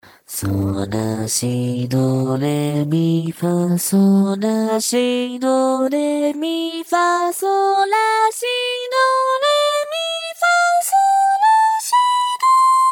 幸JPVCV_凛々しい（sing kire+cool）推薦        DL
G3-A3(G3) C4-D4(C4) D4-E4(E4) F4-G4(F4) A4-B4(A4)